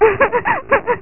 home *** CD-ROM | disk | FTP | other *** search / AMOS PD CD / amospdcd.iso / 051-075 / apd058 / laugh ( .mp3 ) < prev next > Amiga 8-bit Sampled Voice | 1990-10-25 | 6KB | 1 channel | 5,481 sample rate | 1 second
laugh.mp3